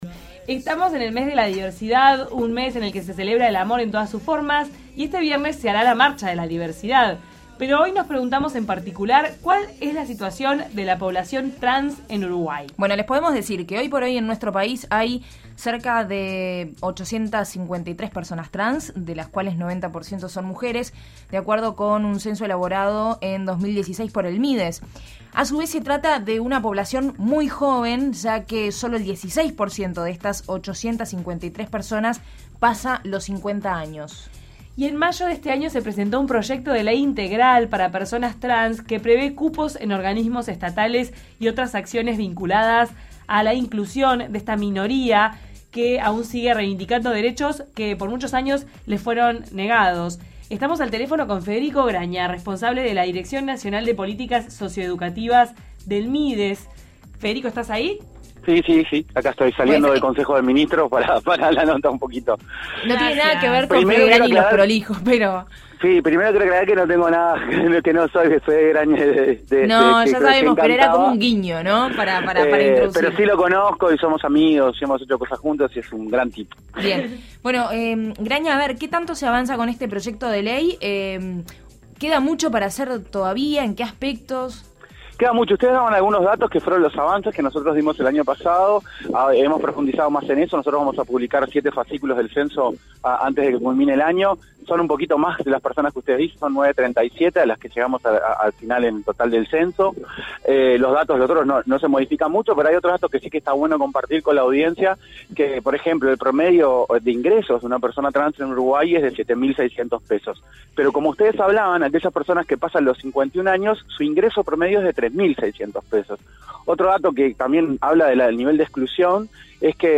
«De Taquito a la Mañana» se comunicó con Federico Graña, responsable de la Dirección Nacional de Políticas Socioeducativas del MIDES.